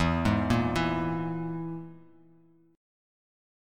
EMb5 Chord